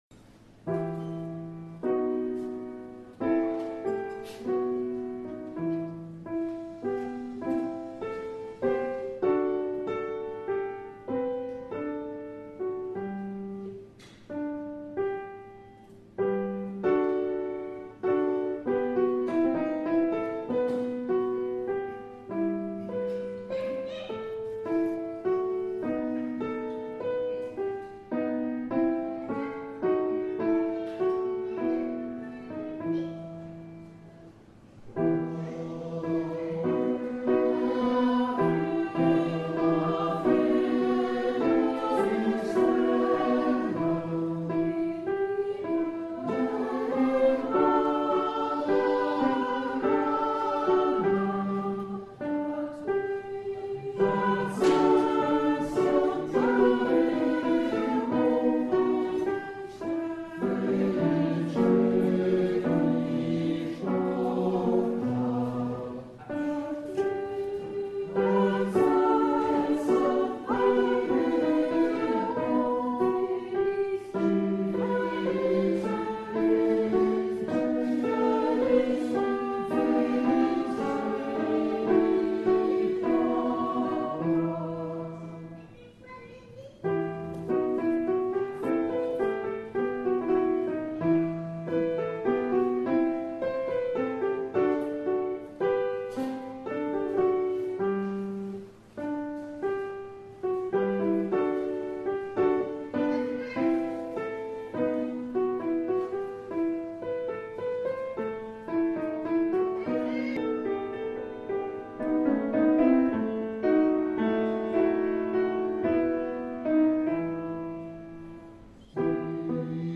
Ensemble vocal PlayBach Lambersart
audition à la Maison Jean XXIII